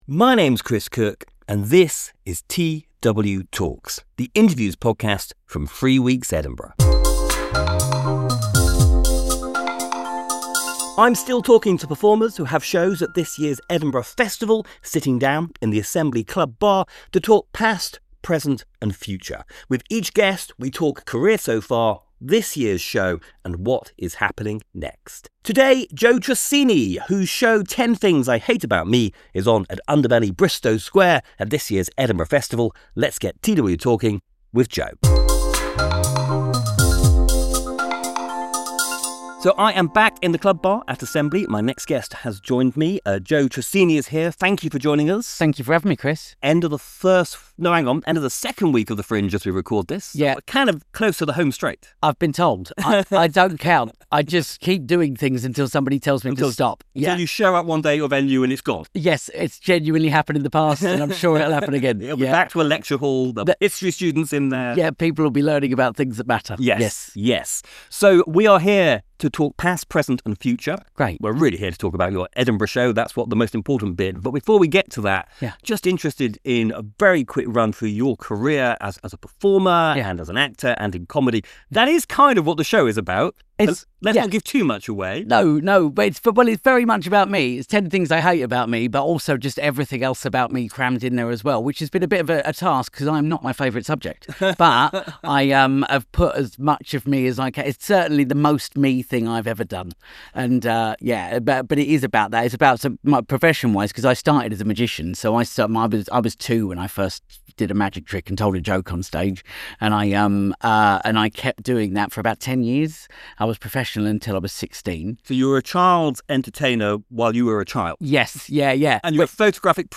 TW:Talks chats to people performing at the Edinburgh Festival 2025, today Joe Tracini about his show 'Ten Things I Hate About Me'.